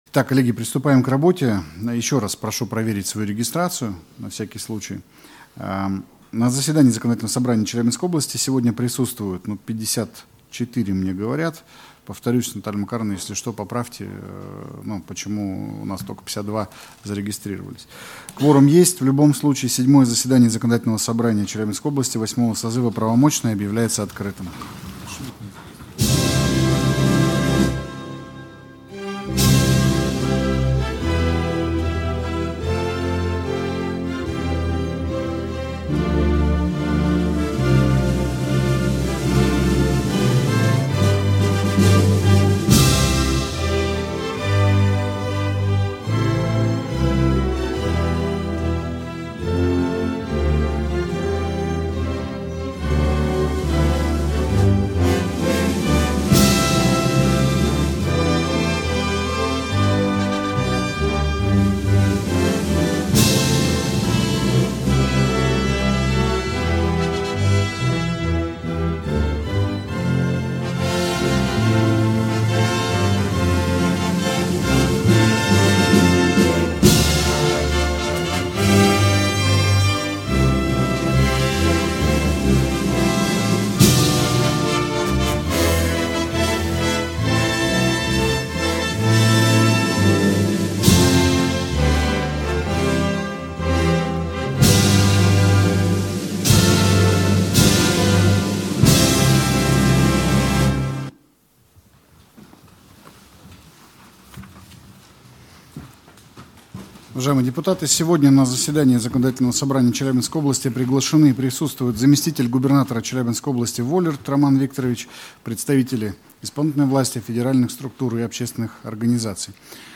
Седьмое заседание Законодательного Собрания Челябинской области VIII созыва